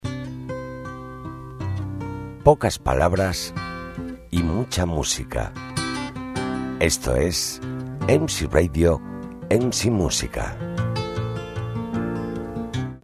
Locutor profesional, más de 30 años de experiencia en radio, televisión , publicidad y márketing
kastilisch
Sprechprobe: Werbung (Muttersprache):